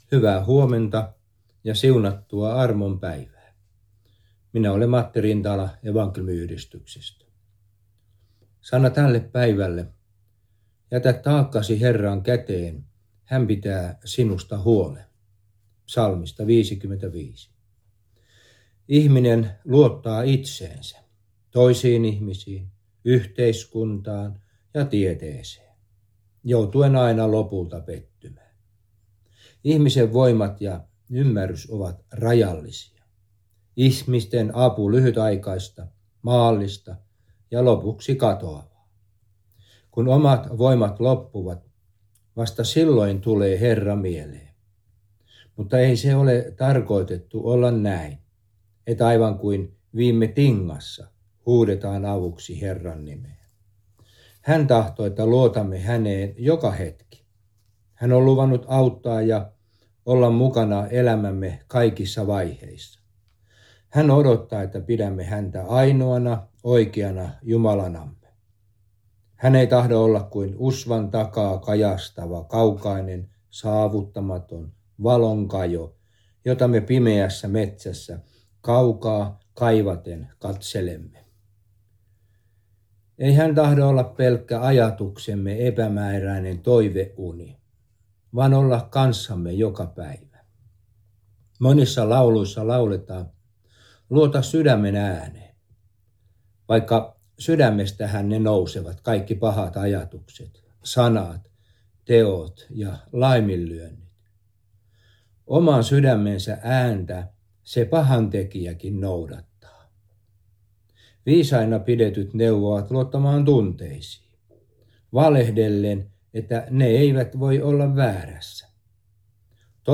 Aamuhartaus
Ähtäri